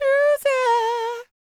DD FALSET023.wav